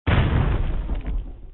B_GEANT_MARCHE.mp3